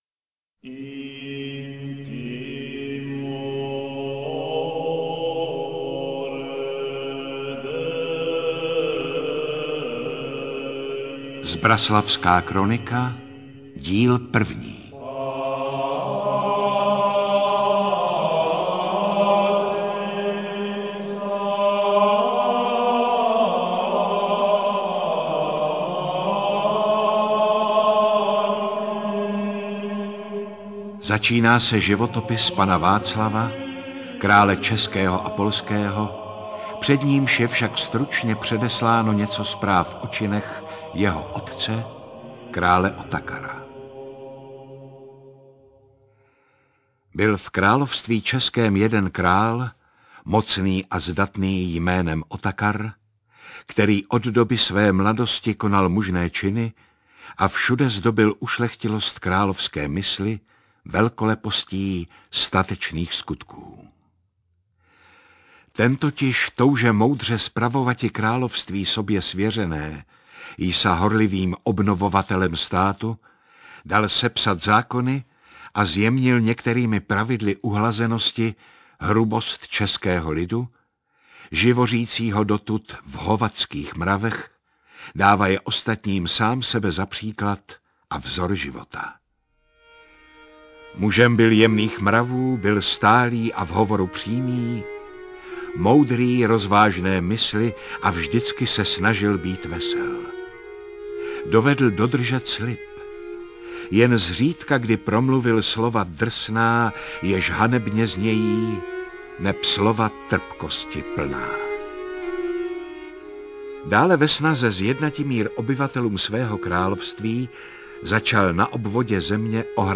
Zbraslavská kronika audiokniha
Audiokniha Zbraslavská kronika - obsahuje rozhlasovou četbu ze středověkého rukopisu.
• InterpretJaromír Meduna